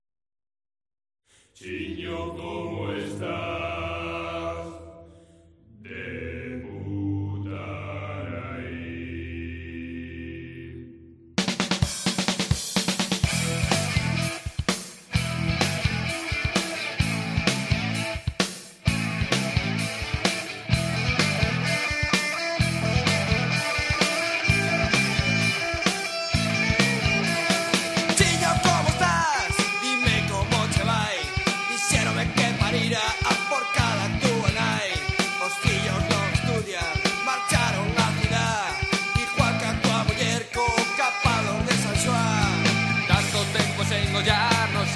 ritmos contundentes
Pop / Rock